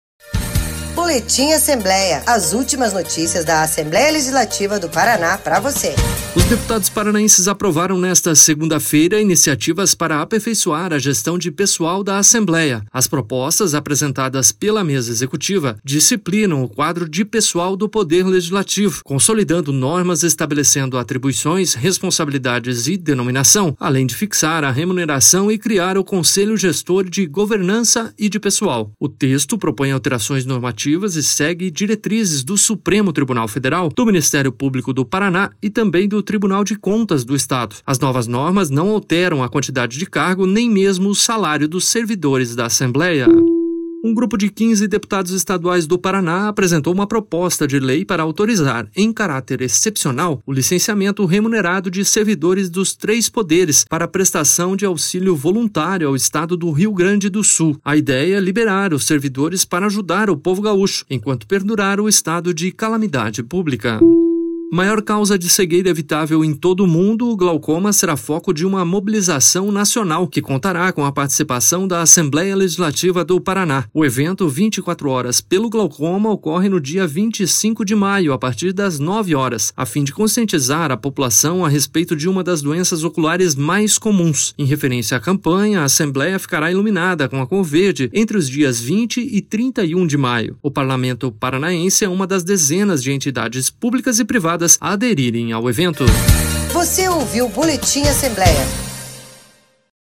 Confira em áudio um resumo das principais notícias desta segunda-feira, 20 de maio, no Boletim Assembleia.